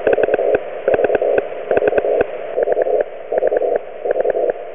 The following edited audio clips feature the letter "v" first sent 3 times on the original FT-1000MP and
The receiver used was a little FT-817 with a 300hz
Please excuse the low level spurious products heard due to overdriving the FT-817 front end
2.0 Khz spacing below fundamental